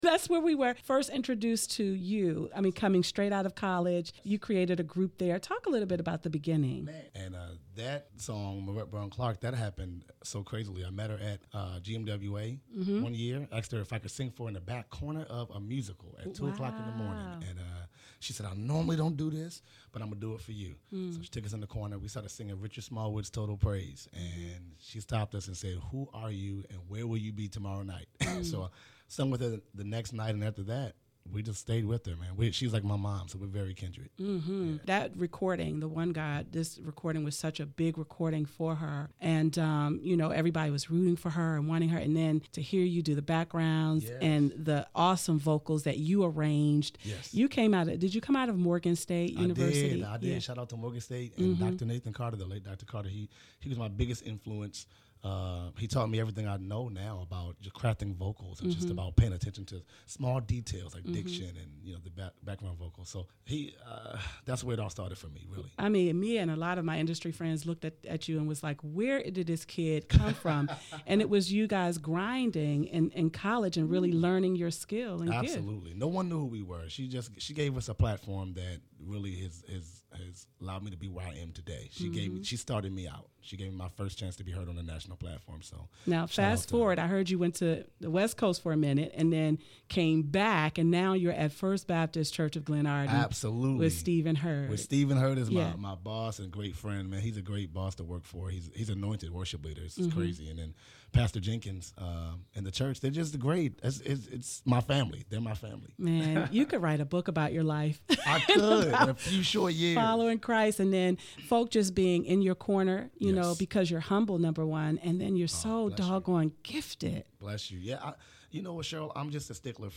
Check out the fun we had in studio here: